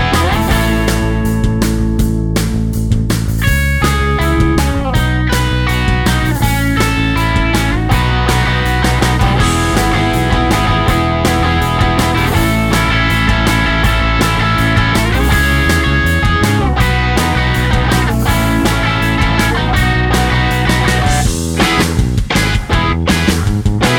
no Backing Vocals Indie / Alternative 3:21 Buy £1.50